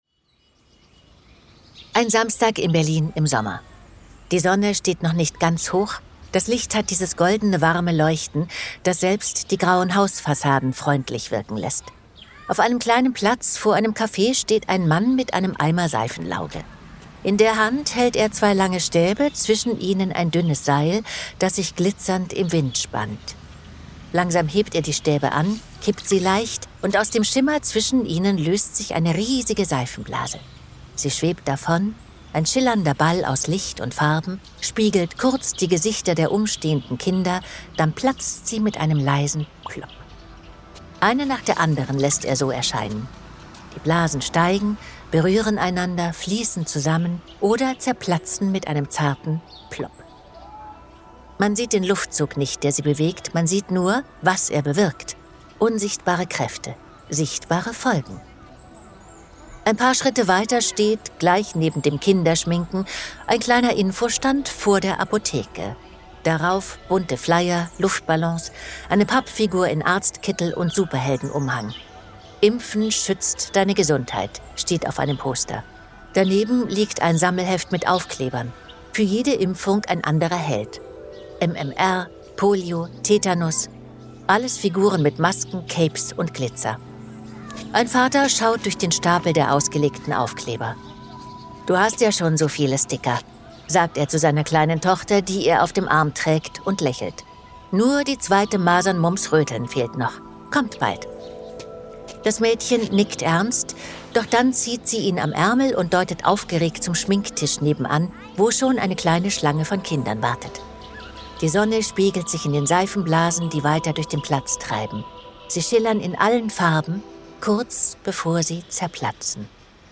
Warum Masern für die Menschheit wieder gefährlicher werden und was einer der wohl größten medizinischen Betrugsfälle der Neuzeit damit zu tun hat, erzählt Andrea Sawatzki in dieser Folge.